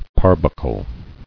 [par·buck·le]